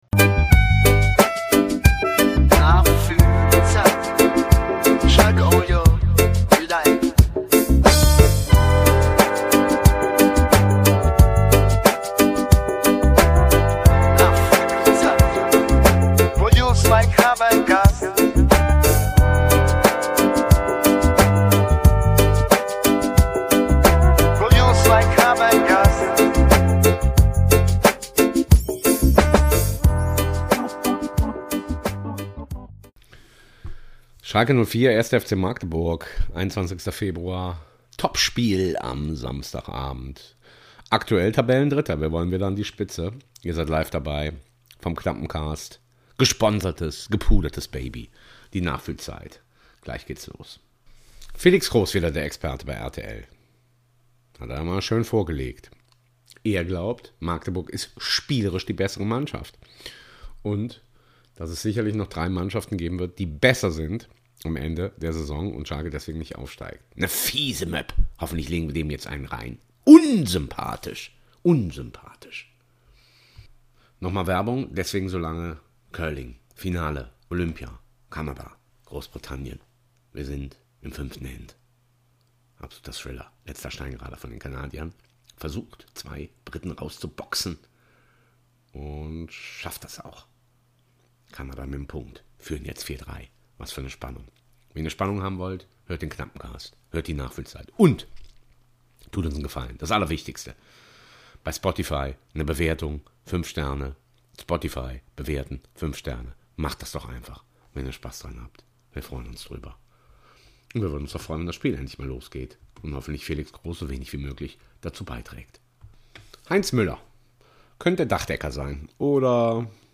Schalke Audio Re-Live vom 21.02.2026